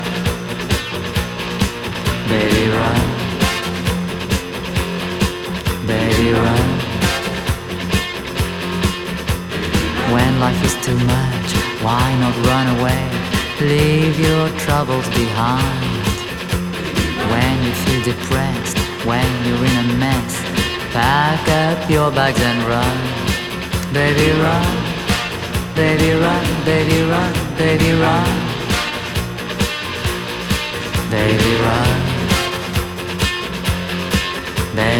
Жанр: Танцевальные / Поп / Электроника / R&b / Соул